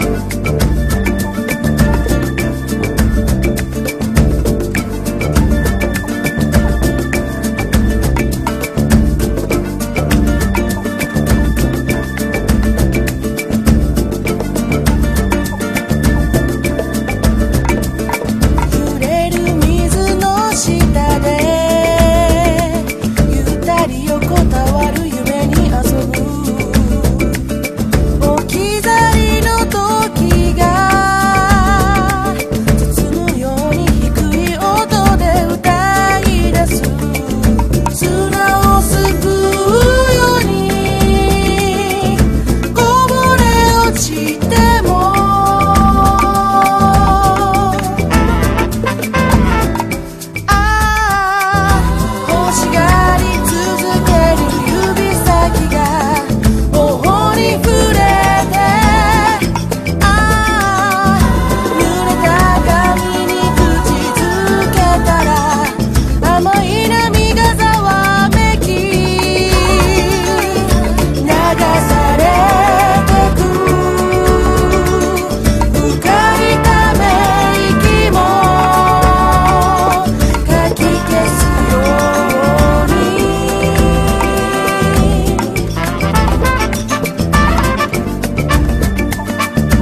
ROCK / 70'S / GLITTER POP / CHRISTMAS / GLAM ROCK
ドリーミィなメロディーが美しいハーモニーとともに広がるクリスマス・ナンバーです。